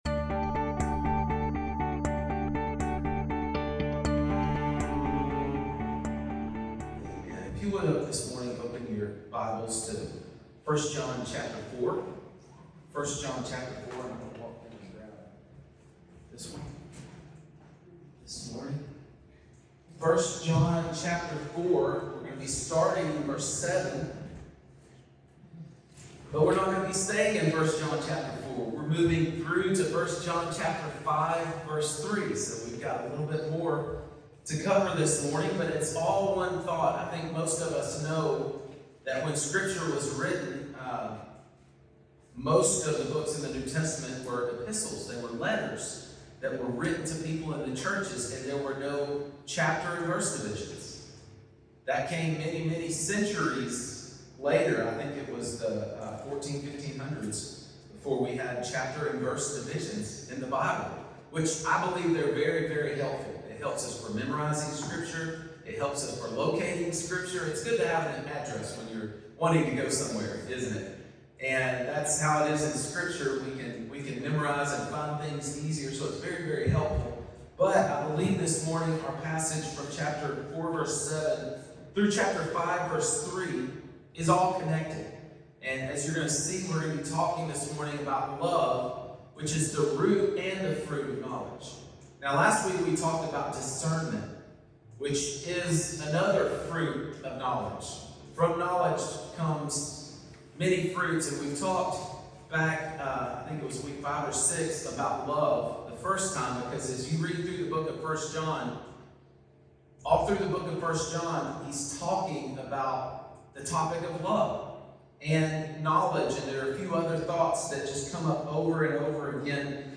A Sermon Series Through First John